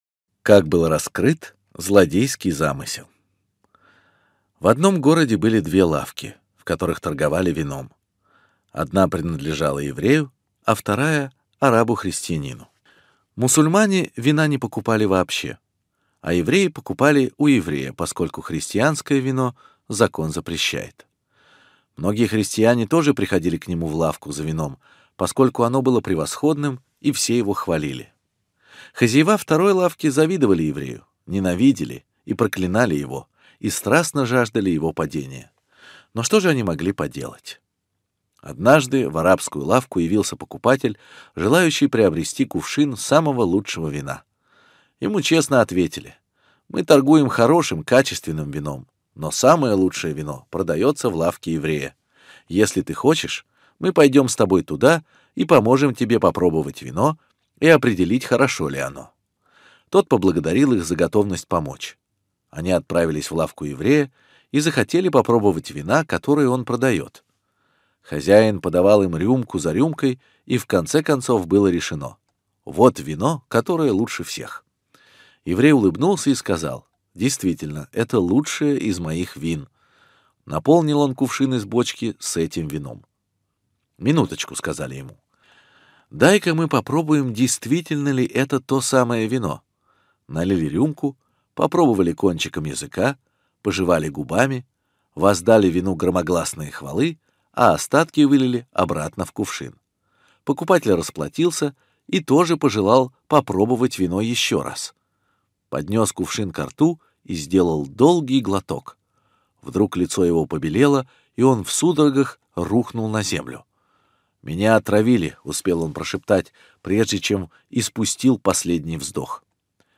Раскрыт злодейский замысел — слушать лекции раввинов онлайн | Еврейские аудиоуроки по теме «Мировоззрение» на Толдот.ру